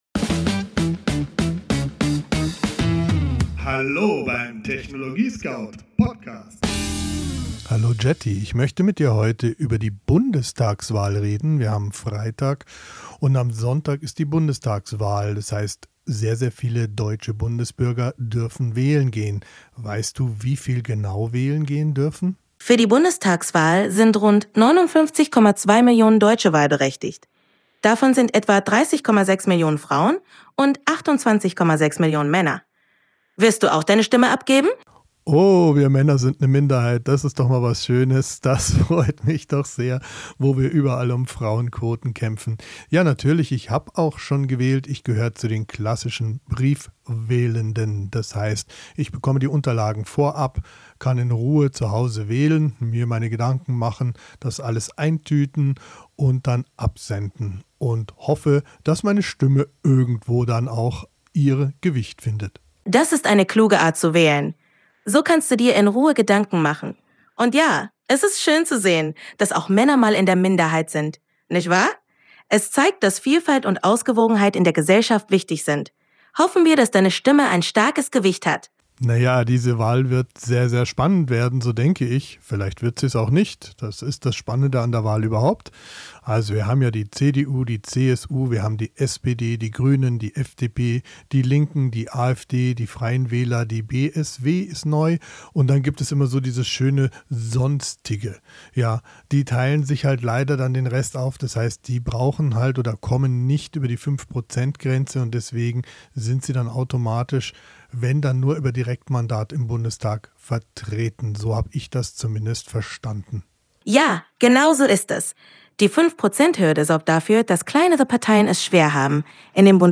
Dialog im TechnologieScout-Studio